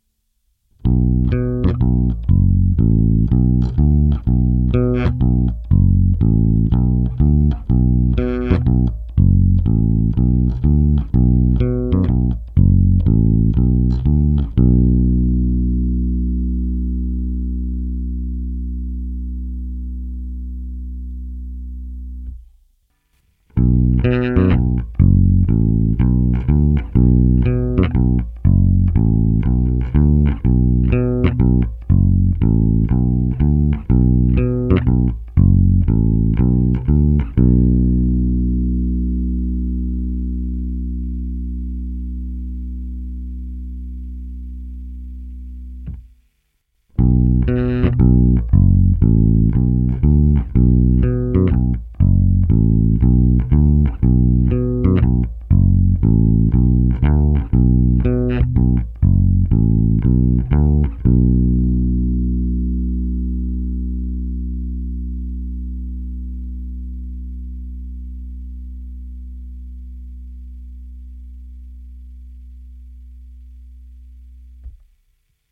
Ernie Ball MusicMan StingRay5
Nahráno s totálně tupými strunami DR Black Beauty, vždy v pořadí paralel - singl - serial.
Korekce v nulové poloze